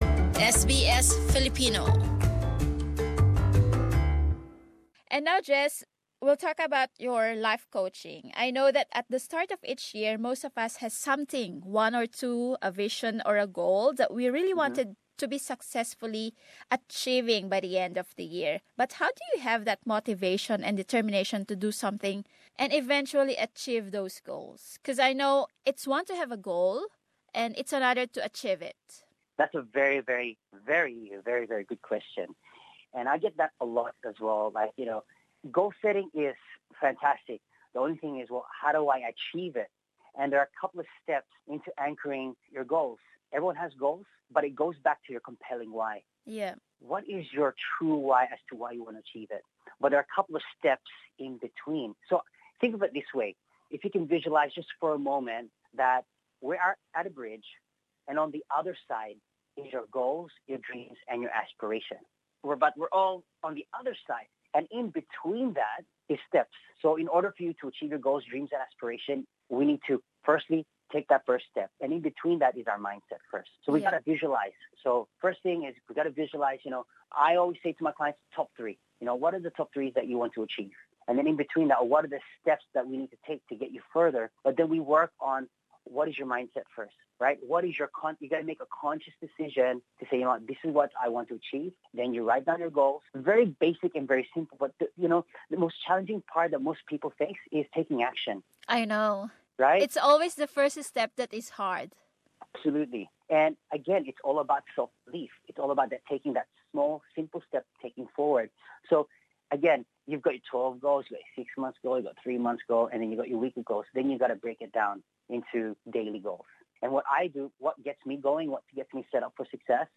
panayam